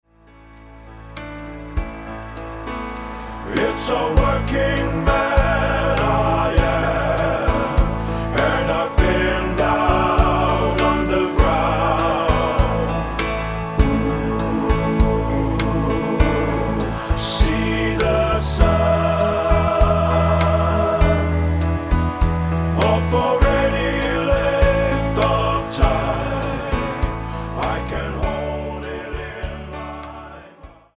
--> MP3 Demo abspielen...
Tonart:A-C mit Chor